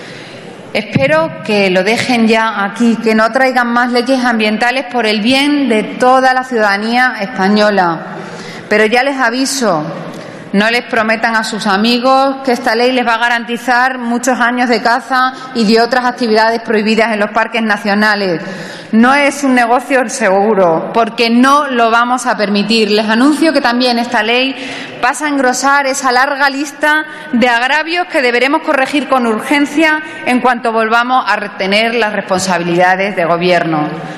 Fragmento de la intervención de Pilar Lucio contra el proyecto de ley de parques naturales del PP 20/11/2014